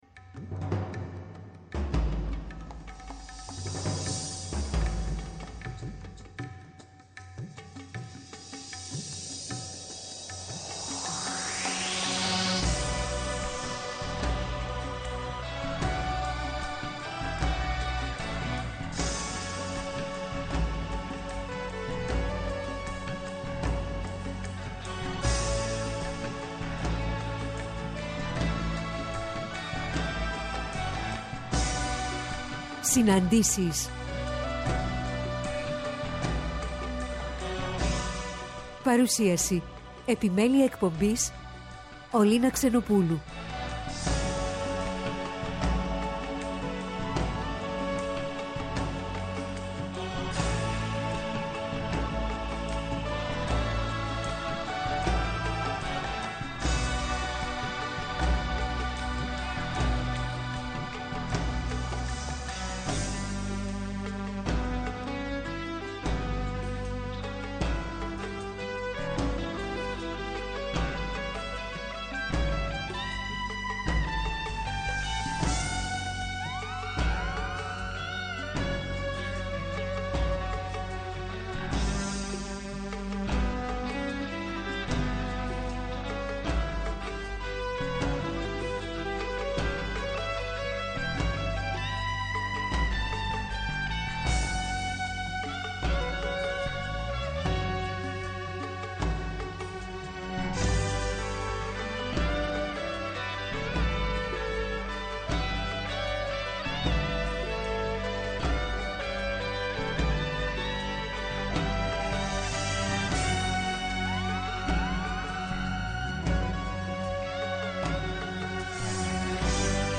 Στις ΣΥΝΑΝΤΗΣΕΙΣ στο ΠΡΩΤΟ ΠΡΟΓΡΑΜΜΑ την Κυριακή 05-03-23 ώρα 16:00-17:00 καλεσμένοι τηλεφωνικά: